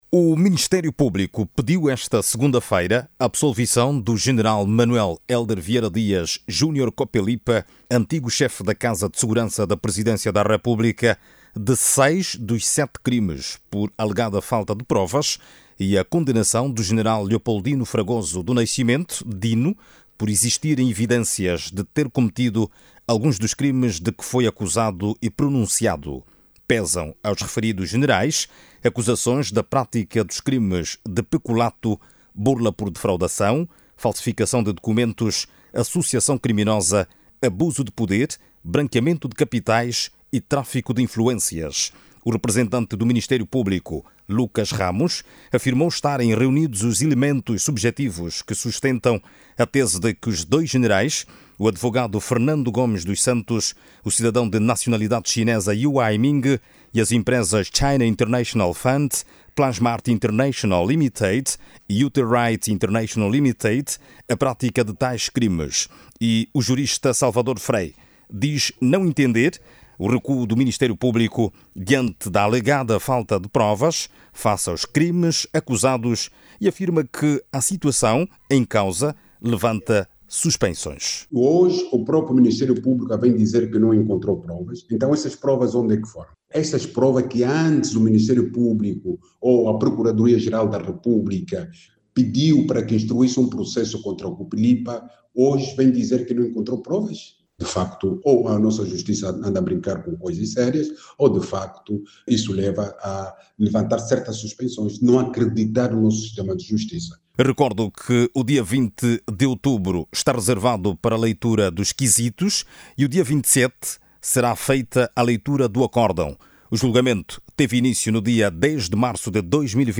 O pedido da Procuradoria Geral da República marcou a fase das alegações orais. Jornalista